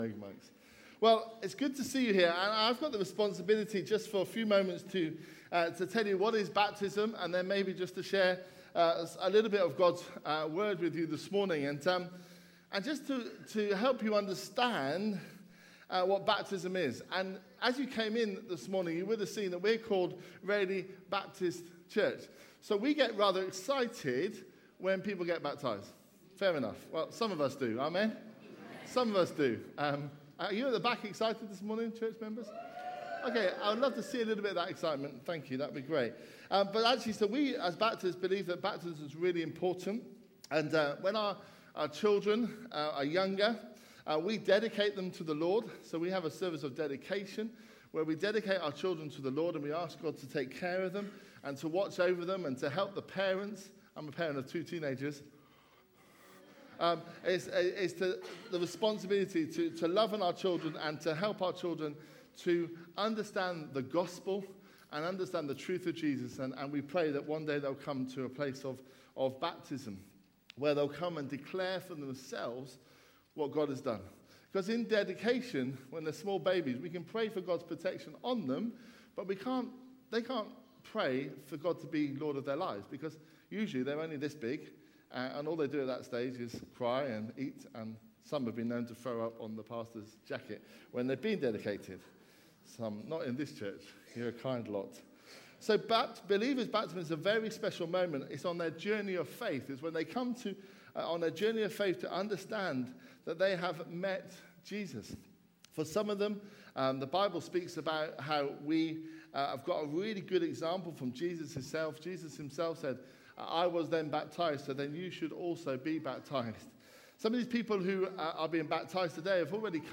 A message from the series "Baptism."
Baptism service